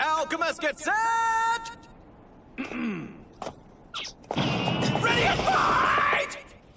Announcer Running.wav